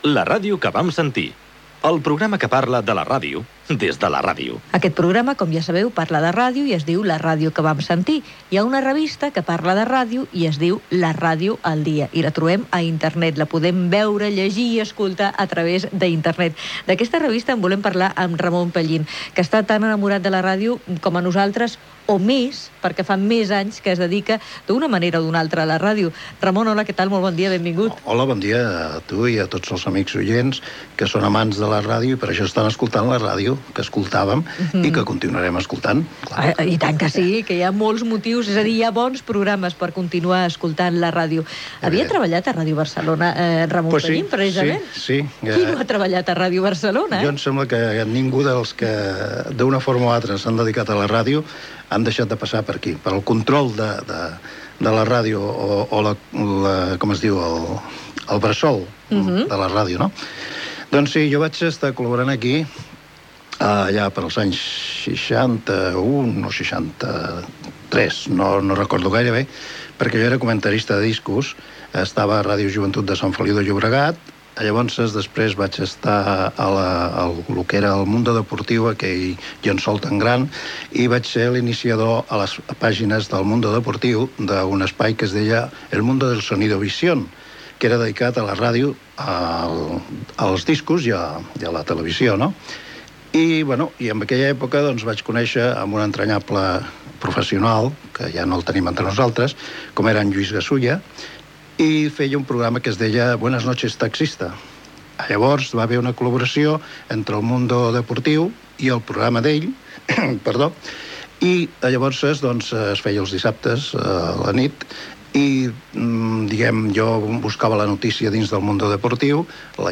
Indicatiu del programa.